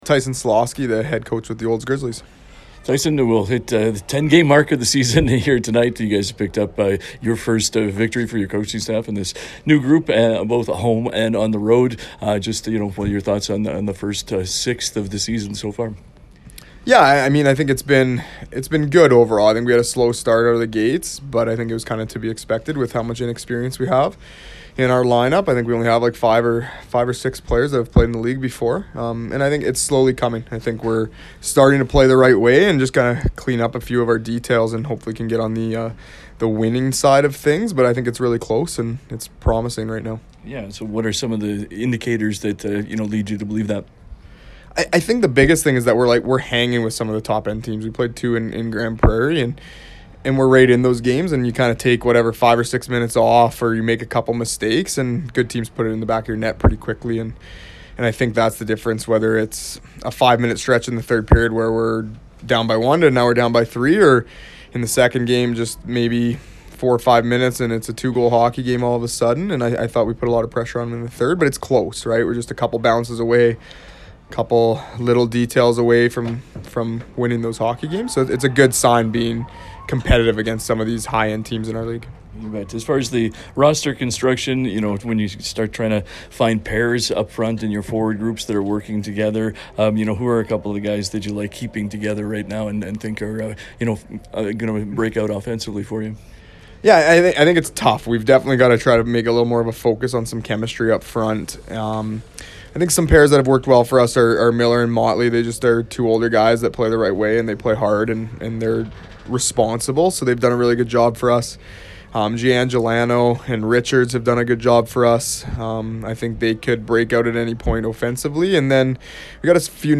pre-game conversation